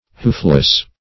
Hoofless \Hoof"less\, a. Destitute of hoofs.